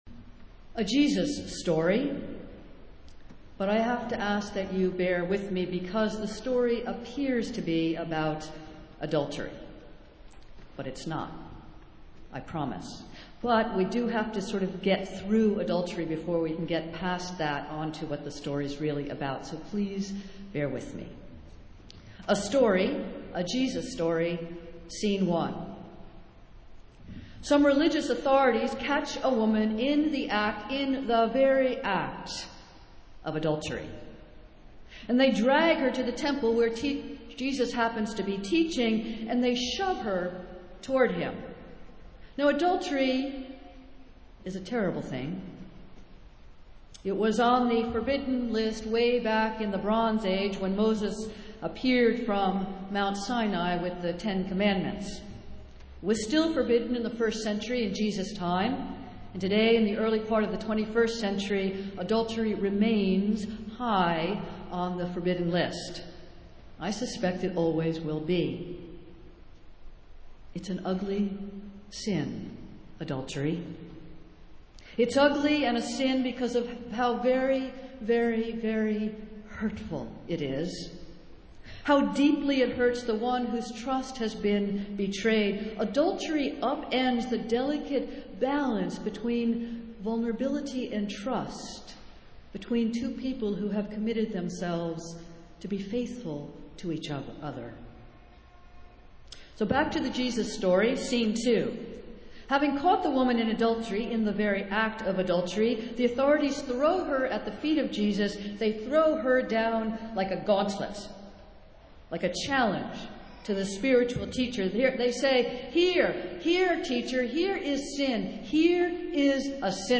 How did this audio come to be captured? Festival Worship - Long-Term Member Sunday